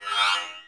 launchMenuOpen.wav